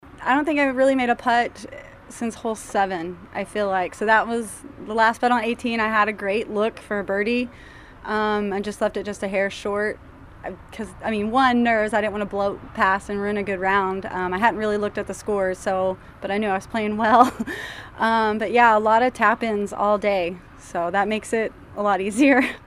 The 3 leaders talked with the Disc Golf Network after the second round.